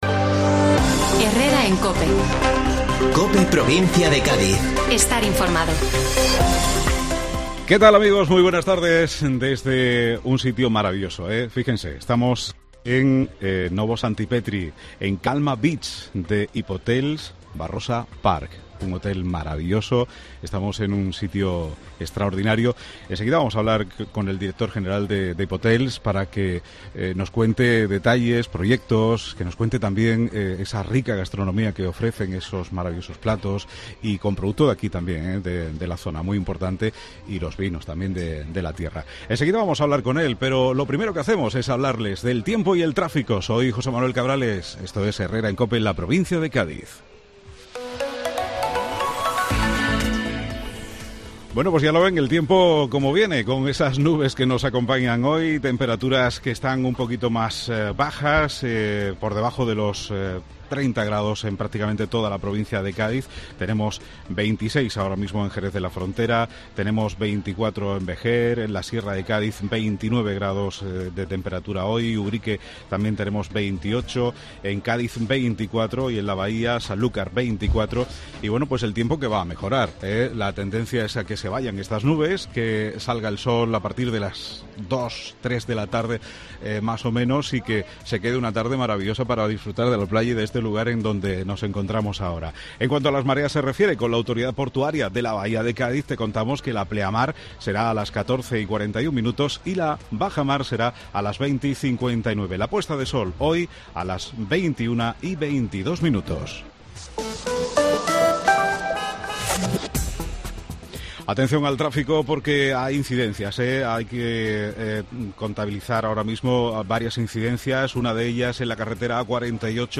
Programa desde Calma Beach de Hipotels Barrosa Parck